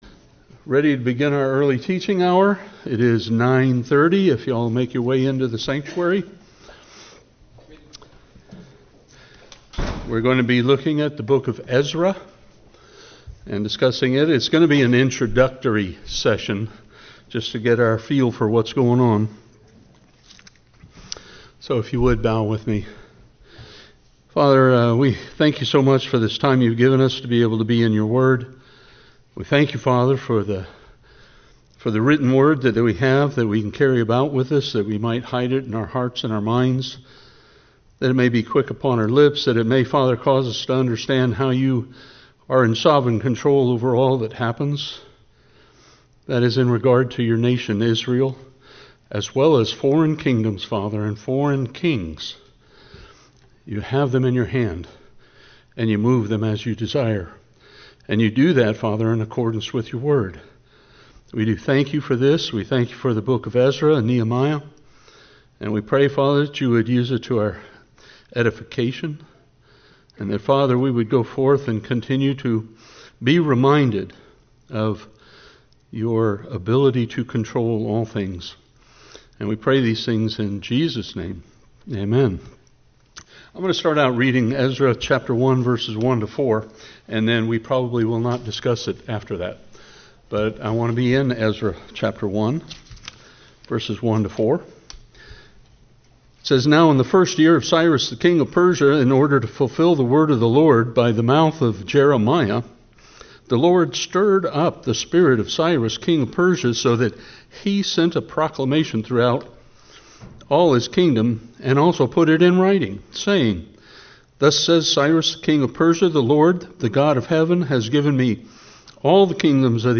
Grace Bible Chapel Non Denominational bible church verse-by-verse teaching